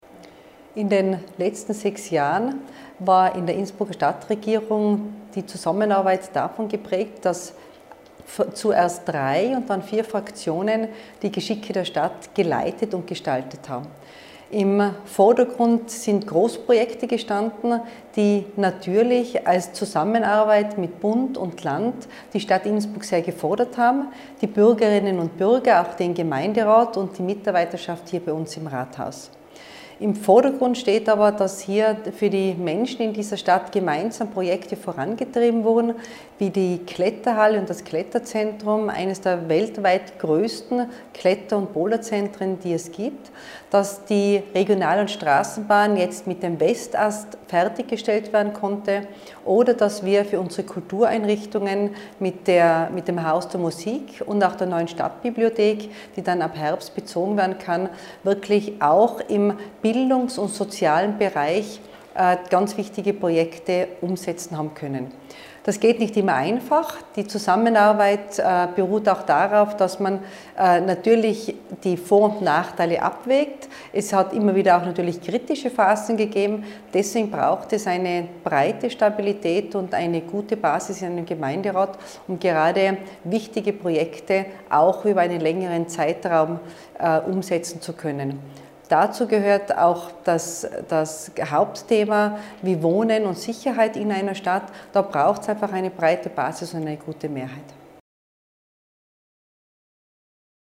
OT von Bürgermeisterin Christine Oppitz-Plörer